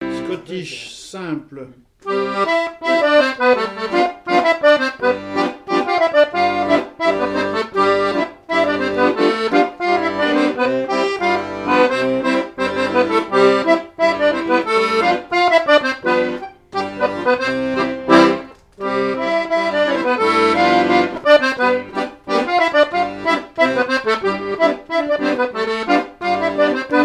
danse : scottich trois pas
airs de danses issus de groupes folkloriques locaux
Pièce musicale inédite